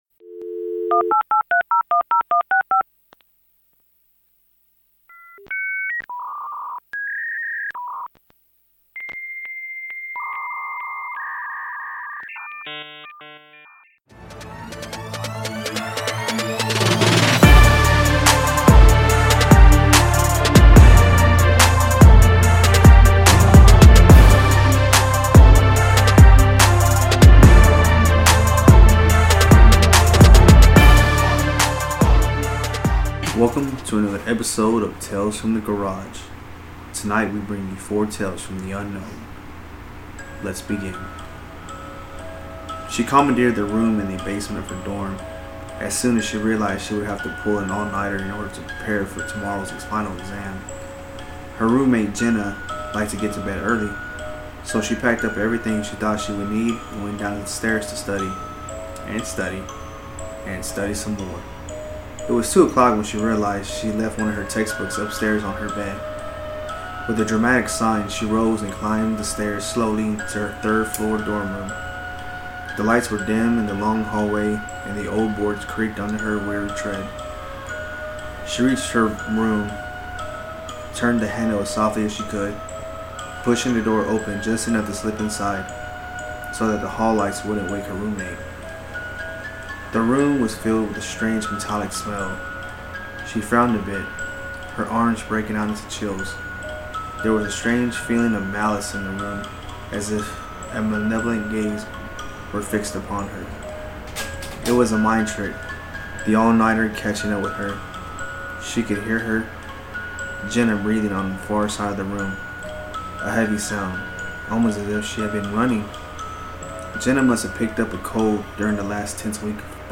We did another deep dive into the mystical river that is Reddit. We picked out some creepy/scary stories that caught our eyes and we read them to you guys. I guess you can call it story time.